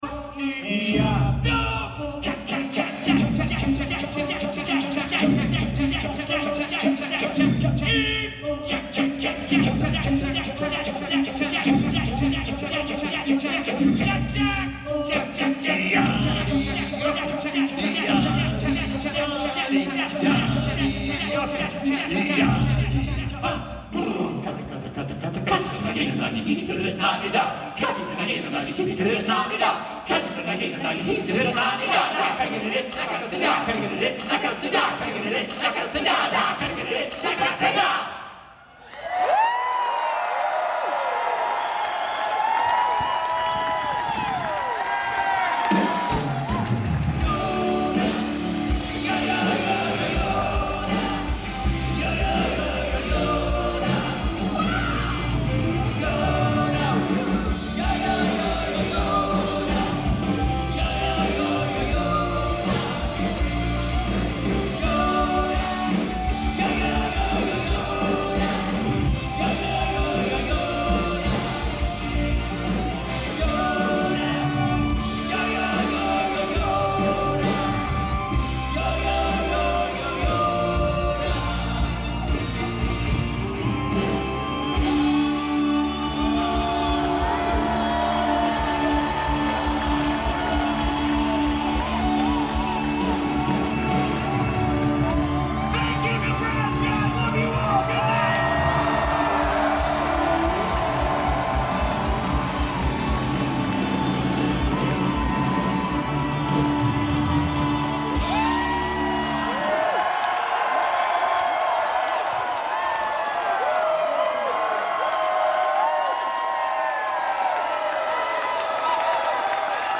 live in concert end of show part 2